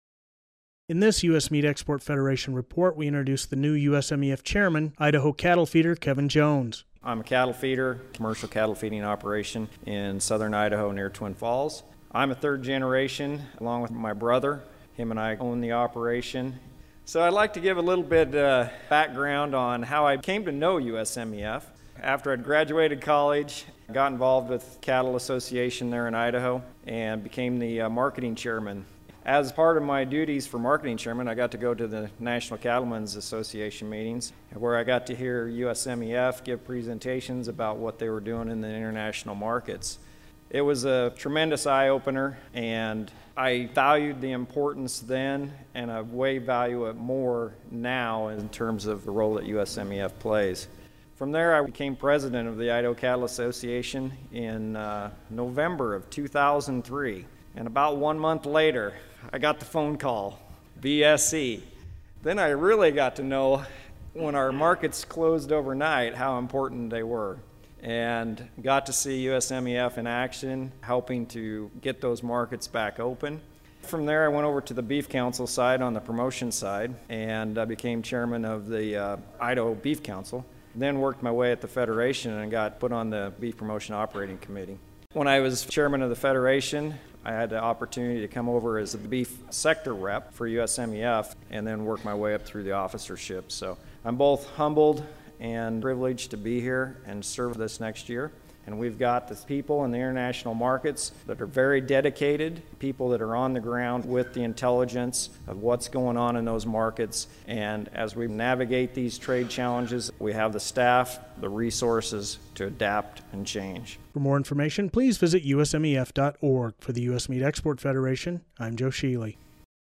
He discusses his background and involvement with USMEF in the attached audio report.